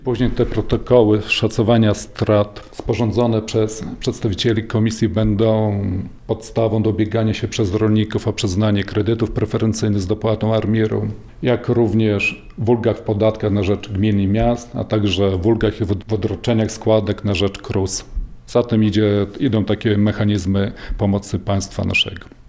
Deszcz już nie uratuje plonów – mówili dziś na antenie Radia 5